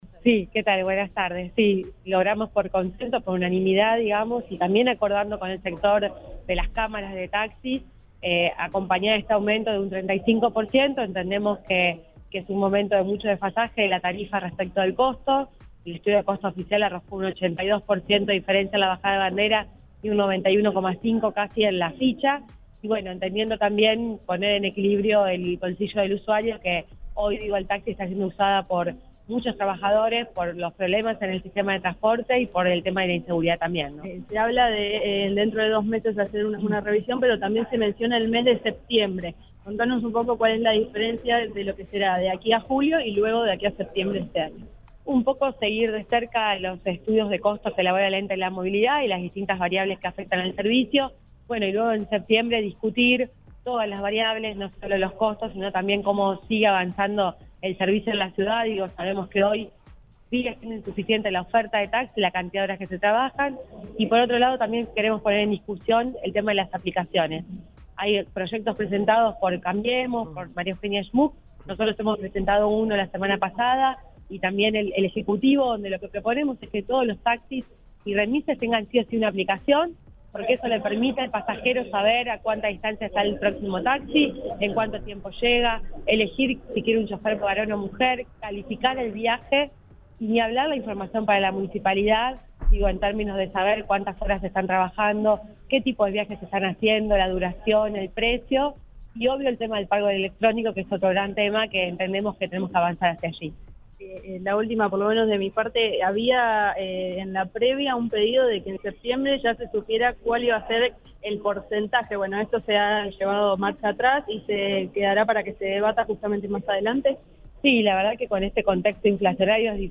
Audio. La concejala Verónica Irizar habló sobre el "consenso" para subir la tarifa de taxis
La edila socialista y presidenta de la comisión de Servicios Públicos, en la que se trató esta iniciativa, Verónica Irizar, valoró ante el móvil de Cadena 3 Rosario que el incremento tarifario salió “a través de un consenso y acordando con el sector de cámaras de taxis”.